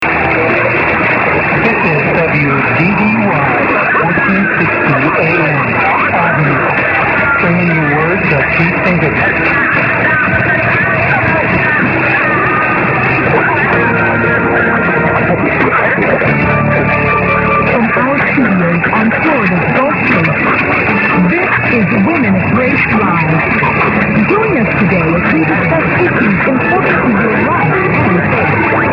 A site mainly dedicated to Mediumwave Dxing